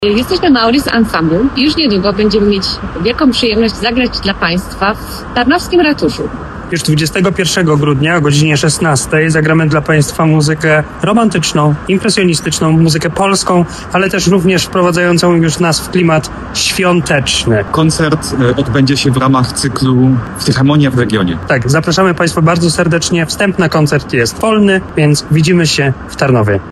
flet
altówka
harfa. W tym kameralnym składzie, zabrzmi muzyka o bardzo różnorodnym charakterze – od barokowej elegancji po impresjonistyczne pejzaże dźwiękowe.